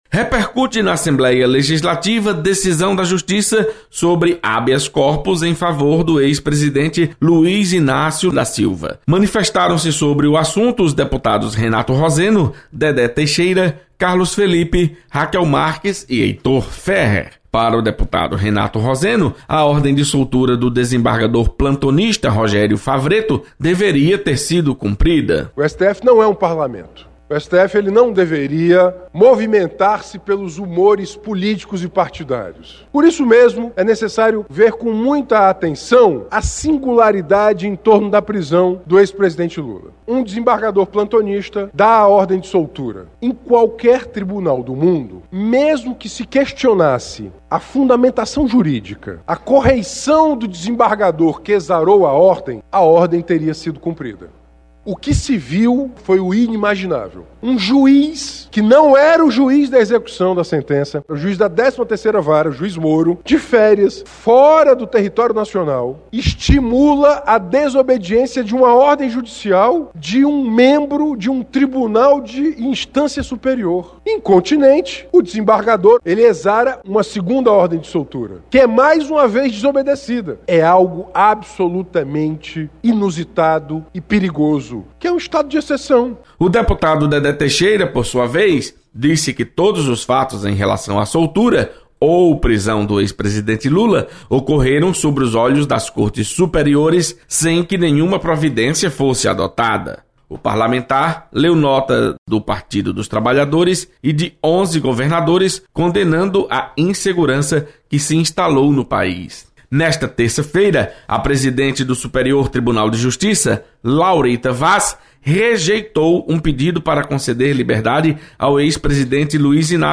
Deputados comentam sobre decisão da Justiça sobre habeas corpus em favor do ex-presidente Luiz Inácio Lula da Silva.